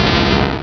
Cri de Sablaireau dans Pokémon Rubis et Saphir.